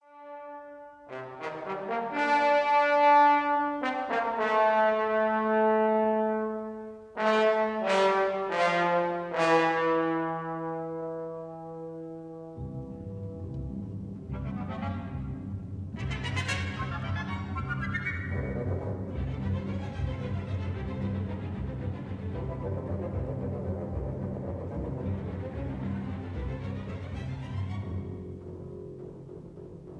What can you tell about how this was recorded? This is a 1954 recording